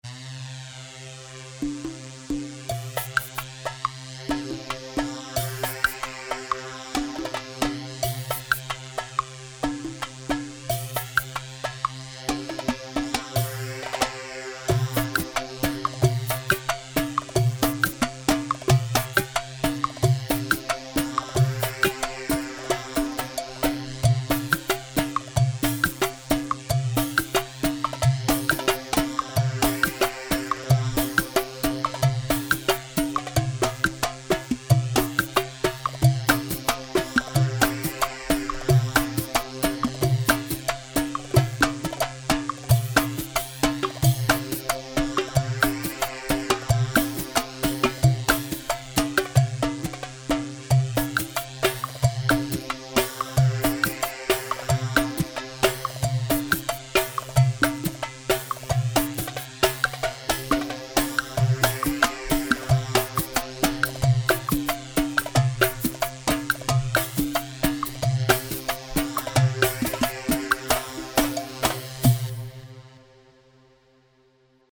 Iraqi
Hewa A 4/4 90 هيوا